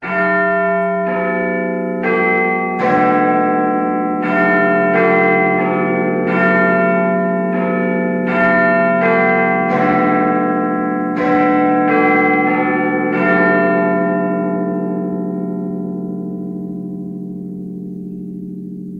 chime.ogg